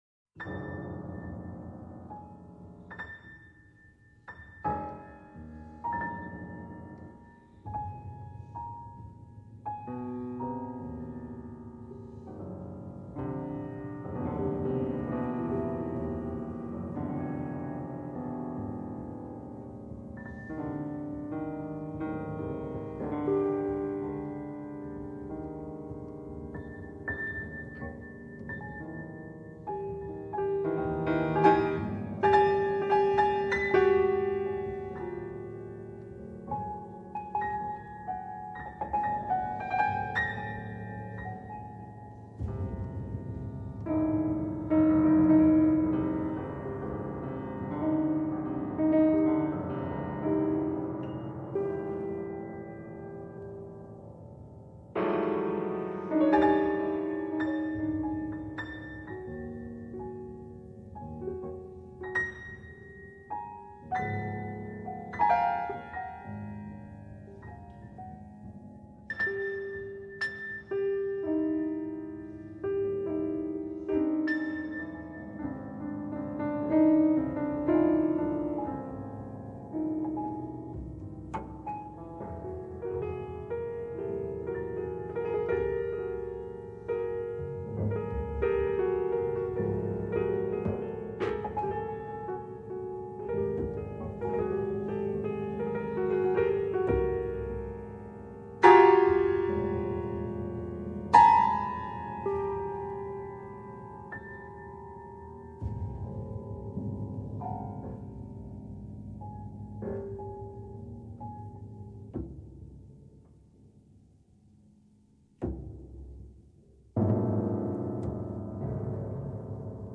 per pianoforte e nastro magnetico